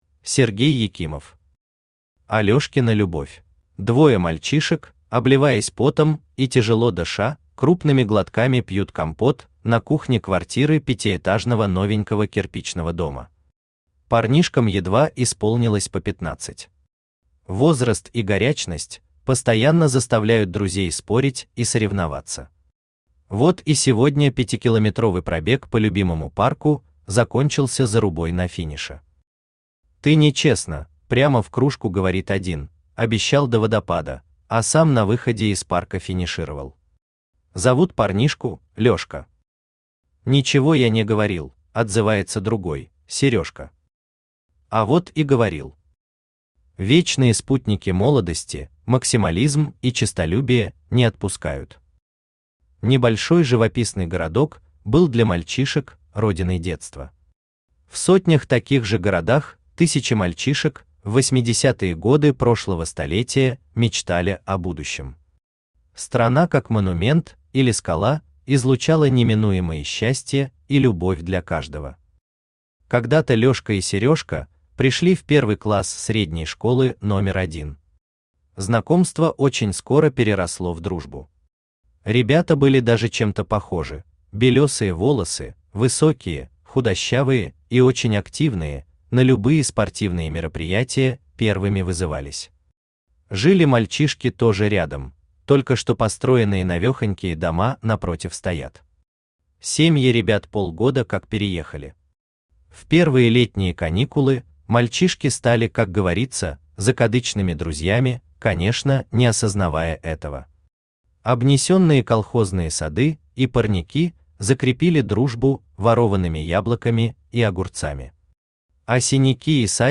Аудиокнига Алёшкина любовь | Библиотека аудиокниг
Aудиокнига Алёшкина любовь Автор Сергей Петрович Екимов Читает аудиокнигу Авточтец ЛитРес.